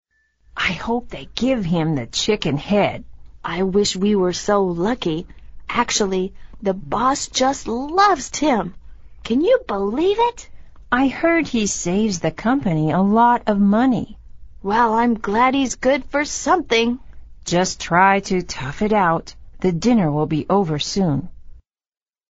Emily whispers to Joice